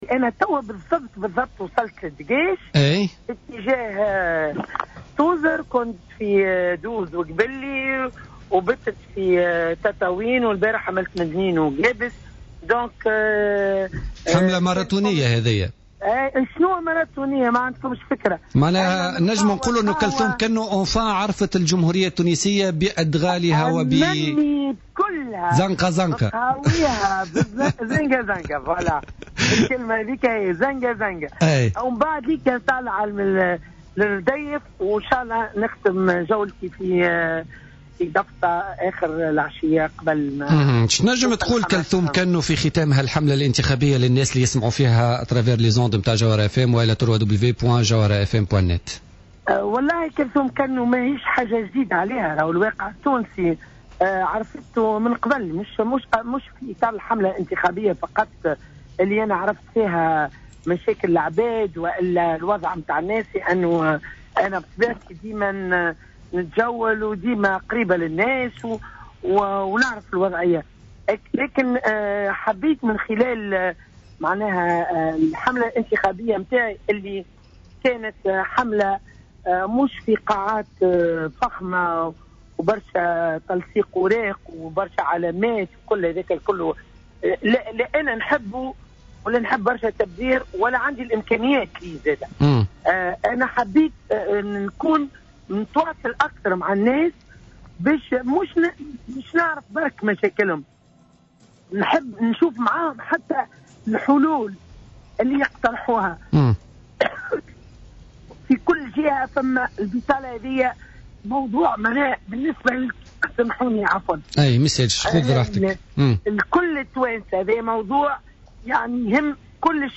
قالت المرشحة للانتخابات الرئاسية كلثوم كنو اليوم في مداخلة لها في برنامج "بوليتيكا" إن الزيارات التي قامت بها في إطار حملتها الانتخابية مكنتها من معرفة كامل مناطق الجمهورية "زنقة زنقة"،وفق تعبيرها.